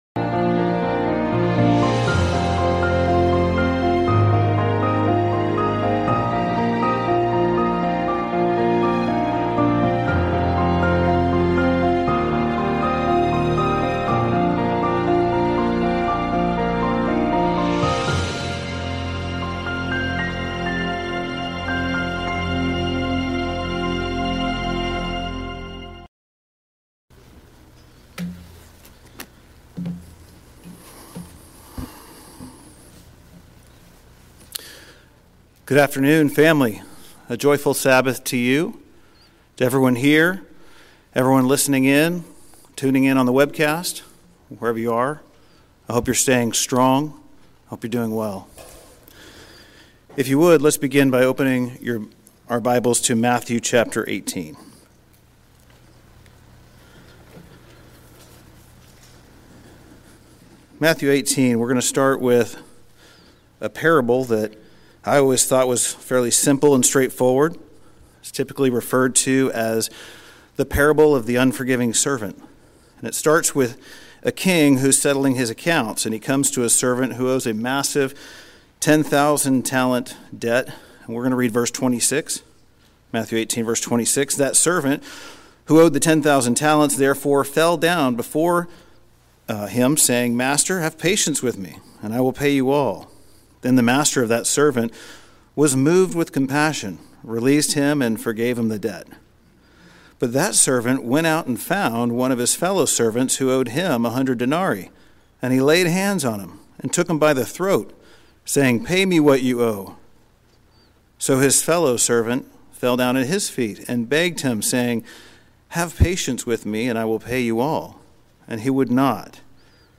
This sermon examines specific Biblical tactics given to us by our Master Jesus Christ for waging offensive spiritual warfare.